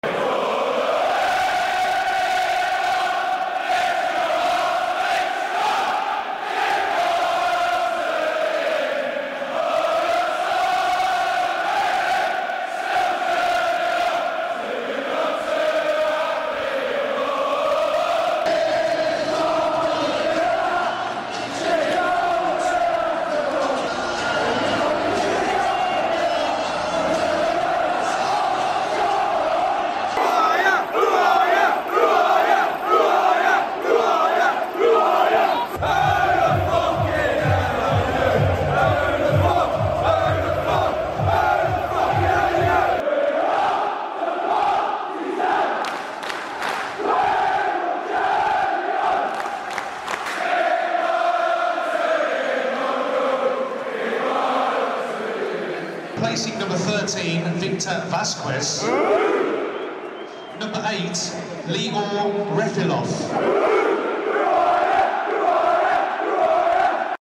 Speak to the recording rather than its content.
Mp3 Sound Effect When they’re up for it, St Andrews can be electrifying!